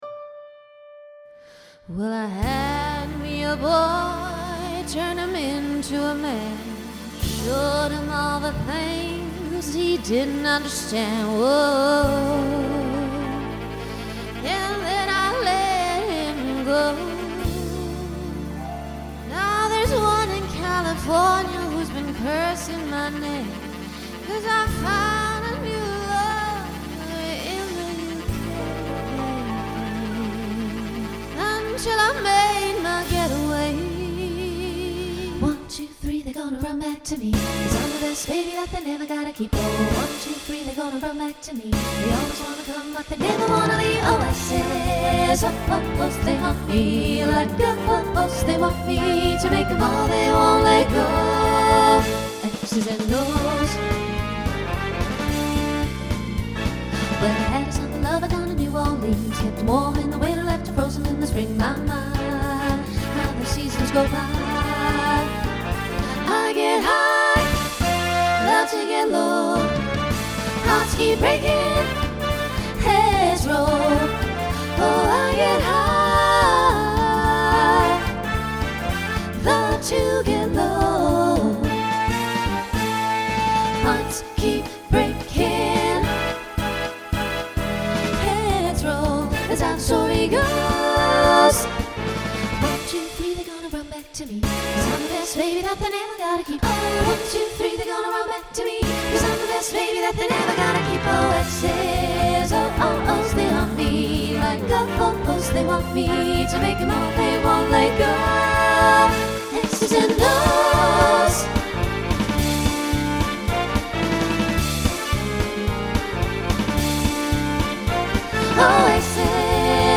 Voicing SSA Instrumental combo Genre Swing/Jazz
Mid-tempo